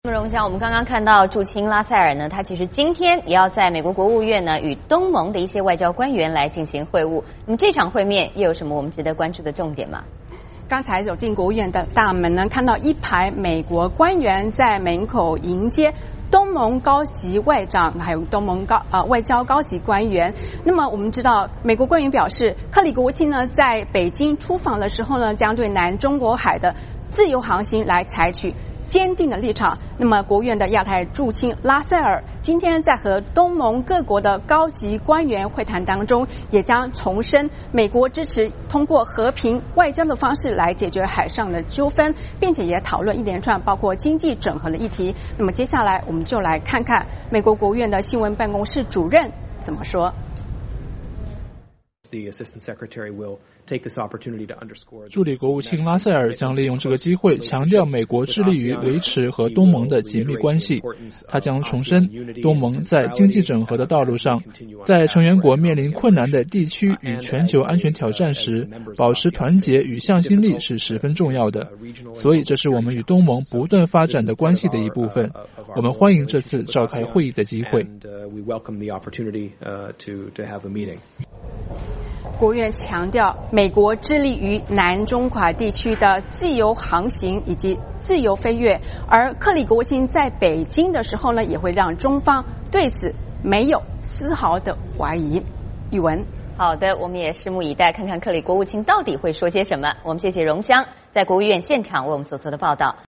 VOA连线：美国-东盟高级官员华盛顿举行会谈